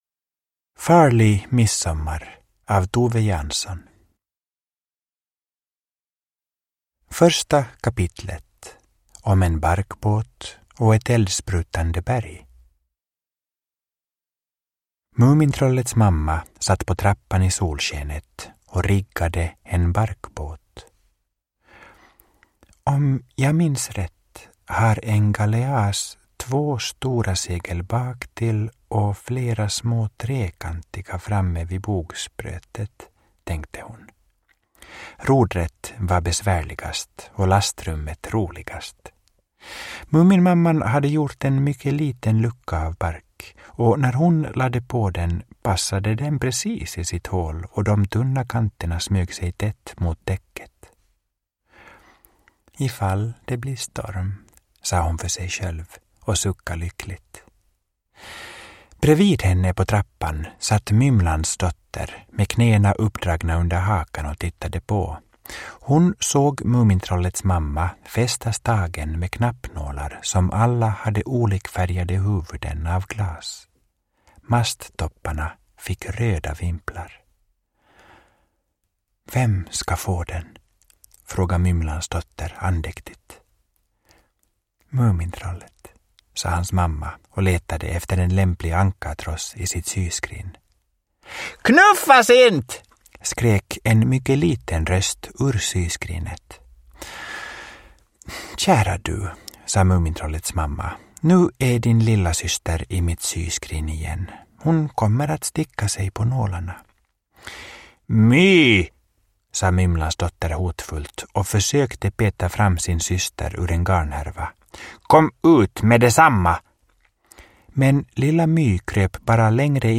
Farlig midsommar – Ljudbok
Uppläsare: Mark Levengood